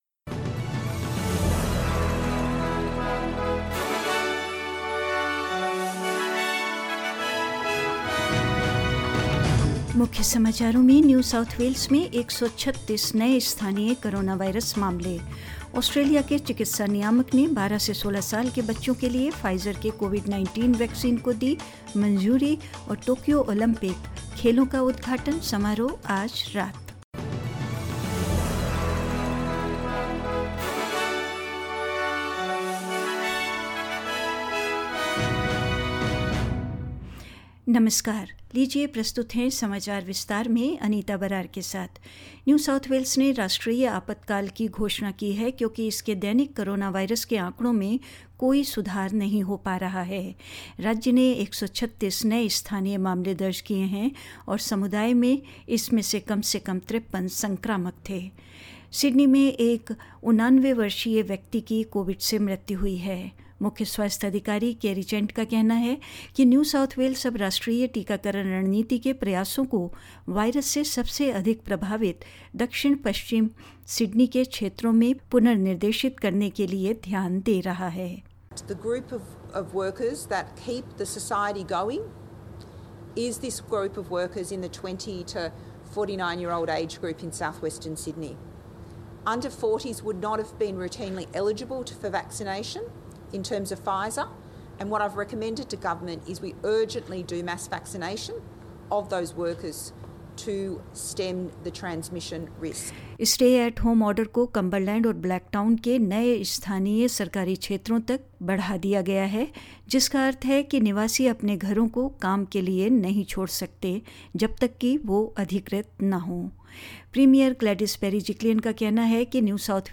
In this latest SBS Hindi News bulletin of Australia and India: New South Wales records its worst day for new Covid-19 infections as it declares a State of Emergency in the state; Australia's medical regulator approves Pfizer's COVID-19 vaccine for children aged 12 to 16; In India, a drone was shot down by the J & K police at Kanachak near Jammu and more news.